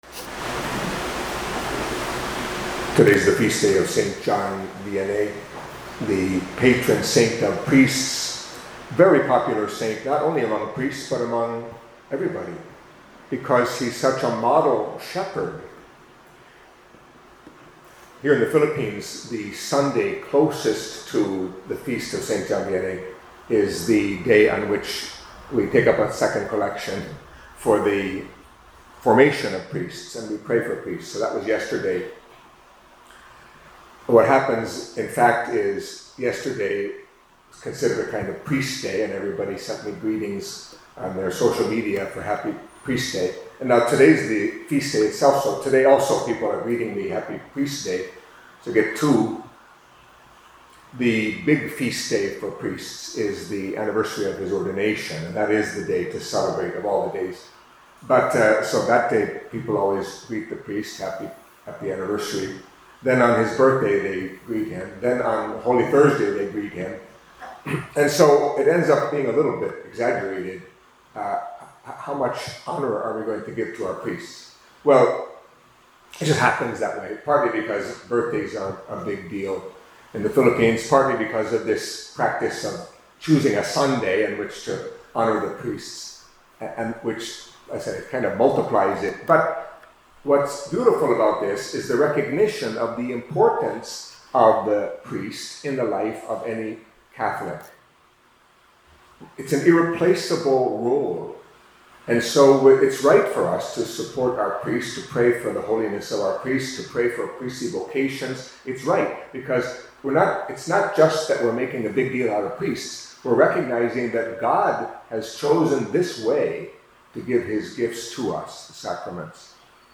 Catholic Mass homily for Monday of the Eighteenth Week in Ordinary Time